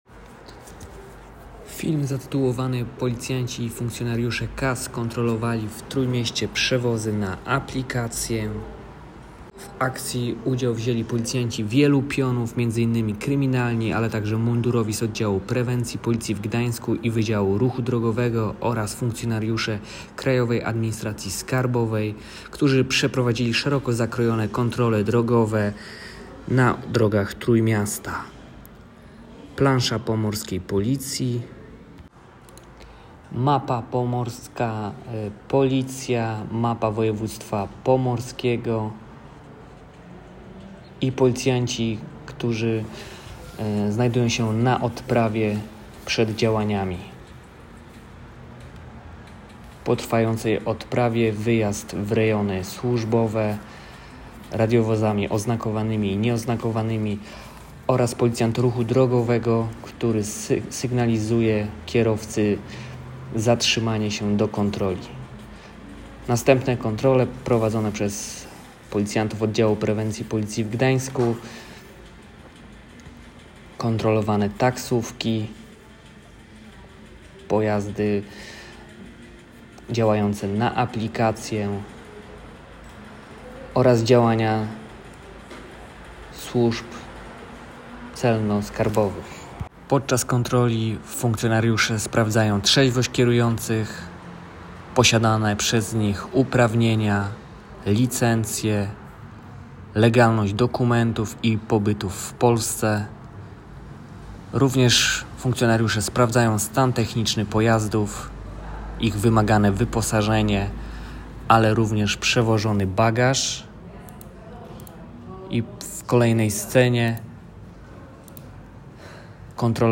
Nagranie audio Audiodyskrypcja.m4a